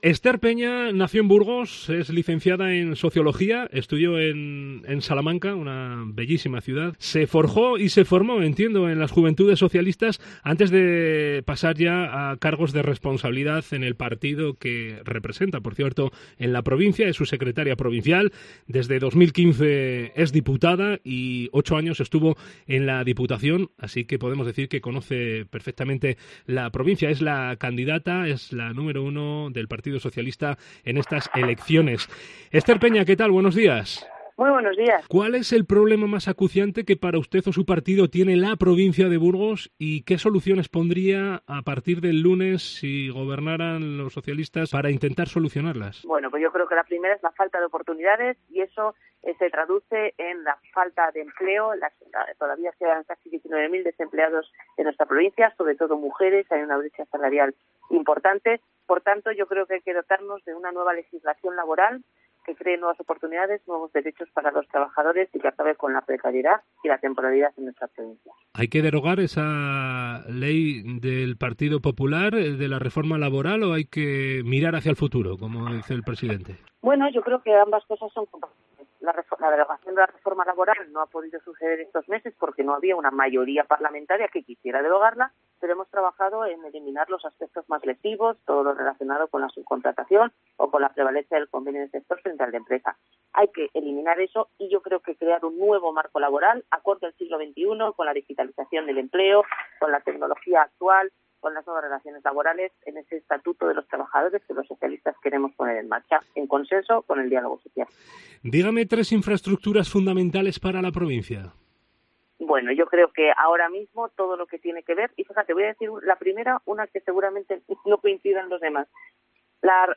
La candidata del PSOE de Burgos al Congreso de los Diputados, Esther Peña, responde a las preguntas